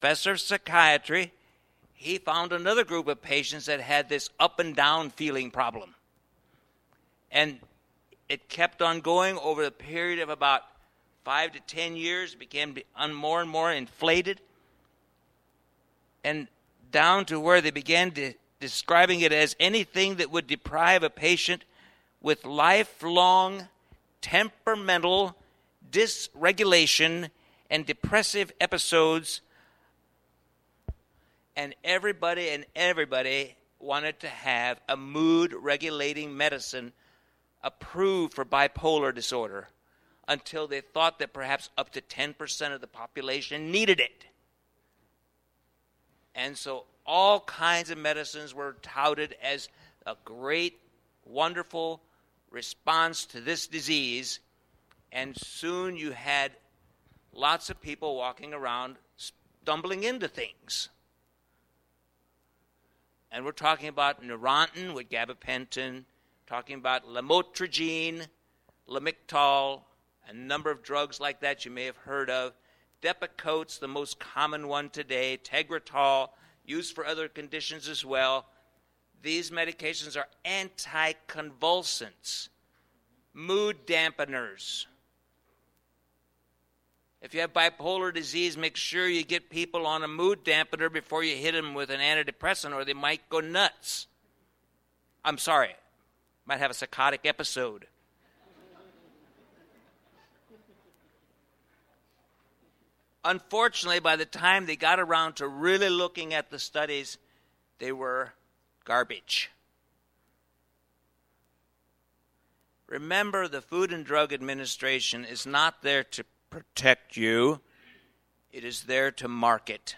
Speaker Guest Speaker